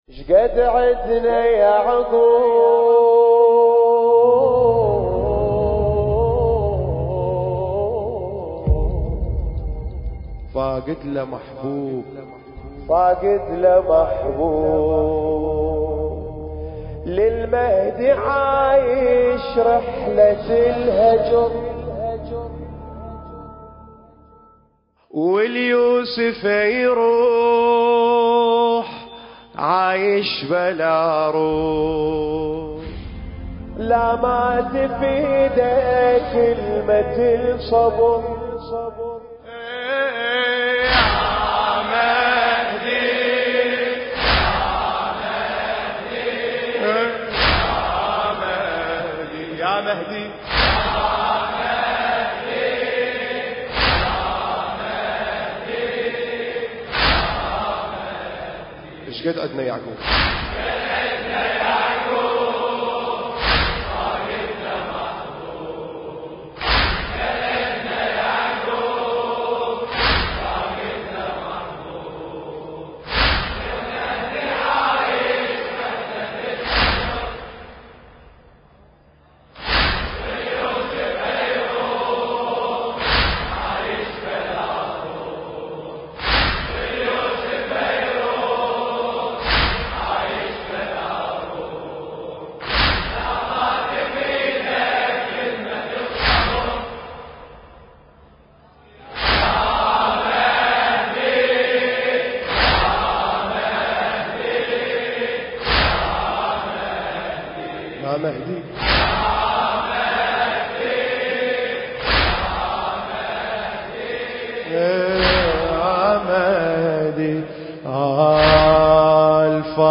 المكان: موكب عبد الله الرضيع (عليه السلام) – بغداد – العراق
ذكرى شهادة السيدة أم البنين (عليها السلام)